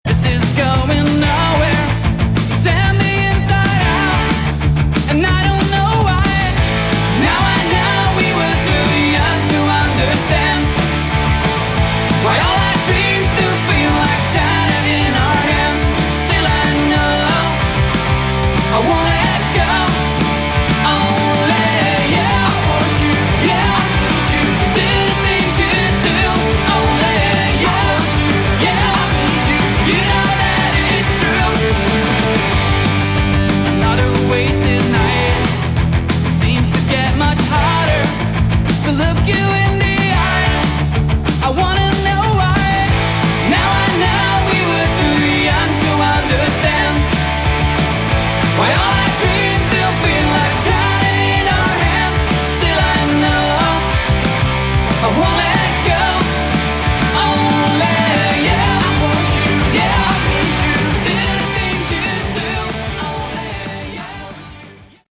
Category: Melodic Rock
Bass
Lead Vocals, Guitar
Drums
Keyboards